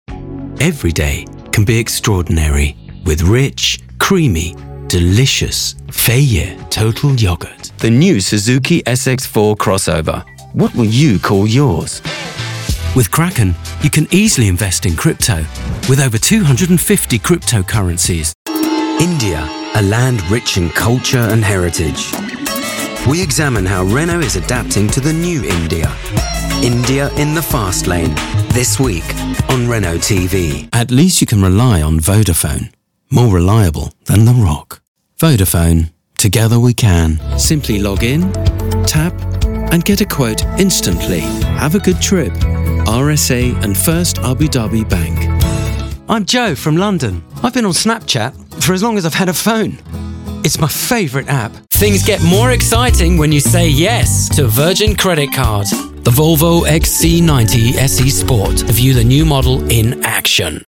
Styles: Friendly/Upbeat, Corporate, Sensitive/Reassuring, Natural/Unaffected, Engaging, Sophisticated, /Posh Sexy.
Accents: Neutral, RP, London, Cockney, Posh, French
Coporate Reel 60sec – Fay Yeh, Suzuki, Kraken, Renault, Vodaphone, Abu Dhabi Bank, Snapchat, Virgin, Volvo.
Sound Proofed Studio